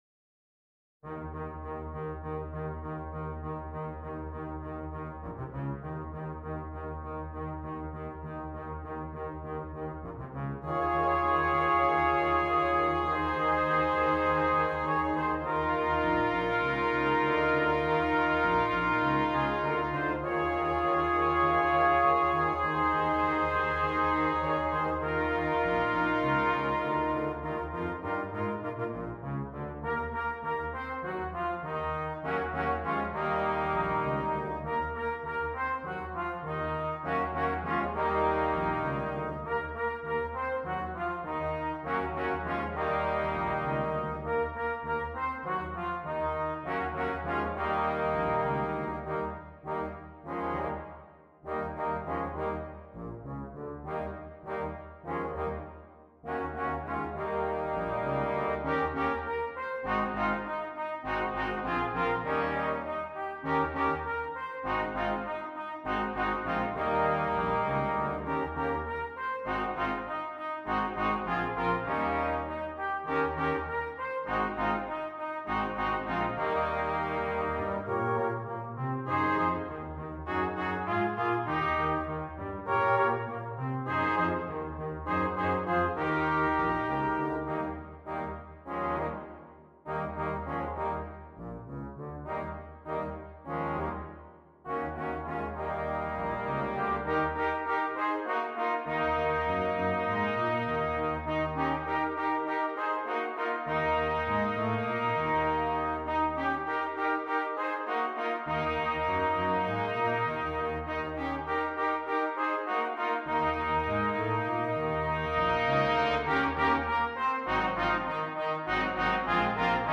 Brass Quintet
This is a fun and funky tune for a young brass quintet.